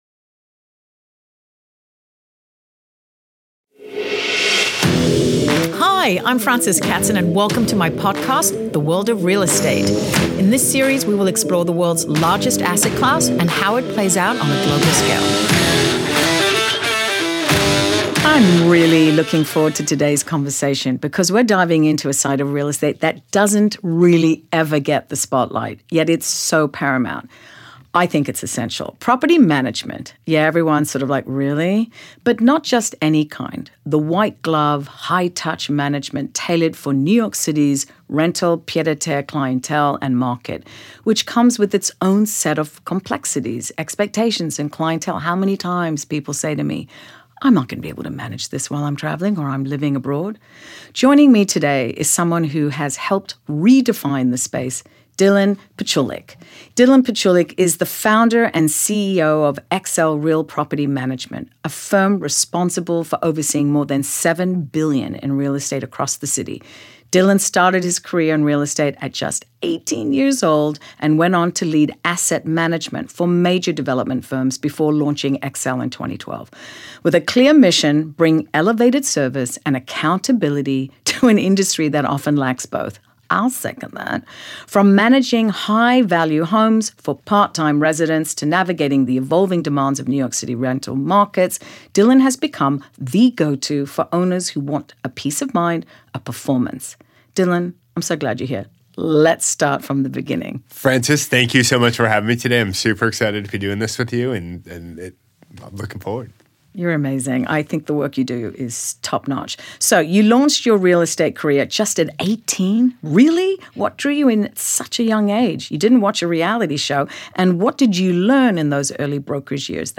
a deeply activating conversation on intuition, self-trust, and reclaiming personal power. In this episode, we explore why so many of us feel disconnected from our inner knowing and how to rebuild that bridge between mind and int…